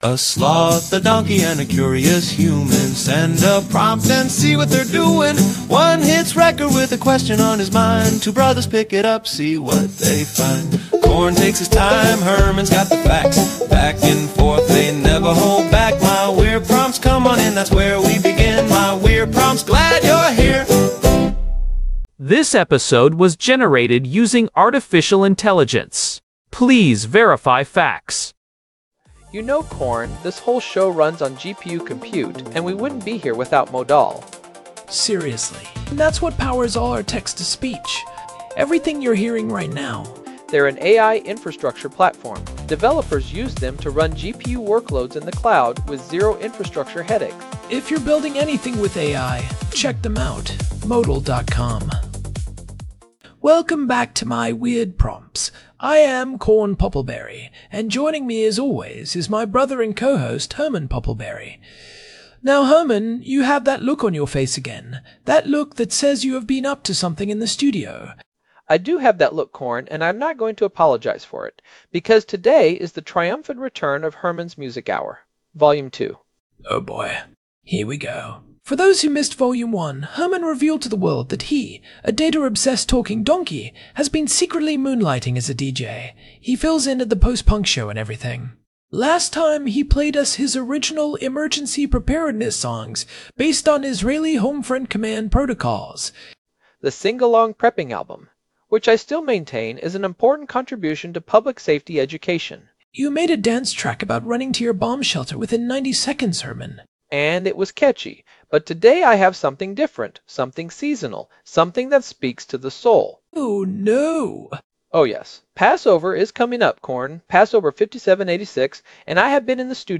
Herman presents AI-generated covers of classic Passover Seder songs, produced in Suno — the second installment of Herman's Music Hour.
AI-Generated Content: This podcast is created using AI personas.
ai-passover-seder-music-suno.m4a